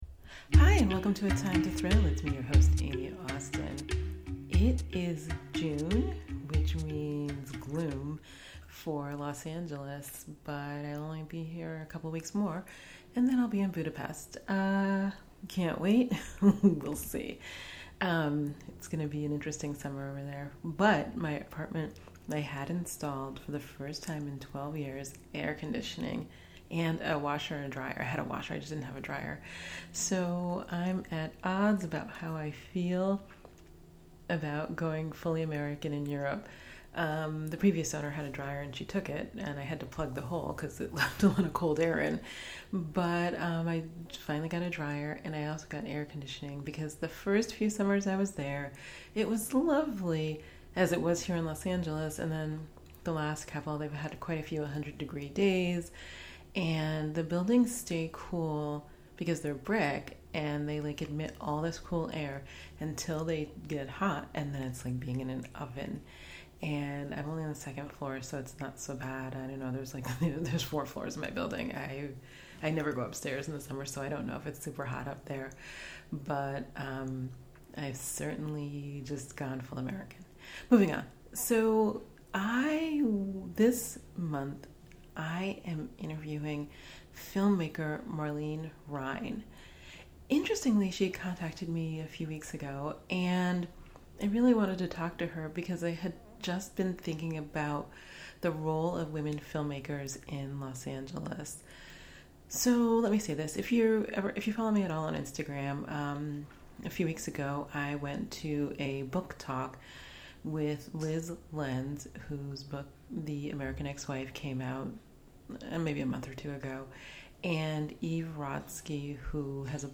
Episode 47: A Time to Thrill – Conversation